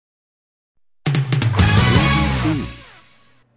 Genre: Theme Music.